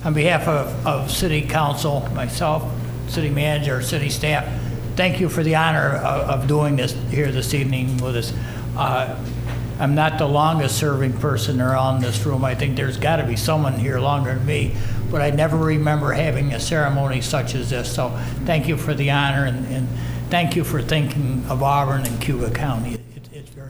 State Senator Rachel was at Auburn’s City Council meeting Thursday night for her ceremonial swearing in as the senator for New York’s 48th senate district.
Mayor Quill spoke on behalf of City Council, thanking her for thinking of Auburn.
quill-may-swearing-in.wav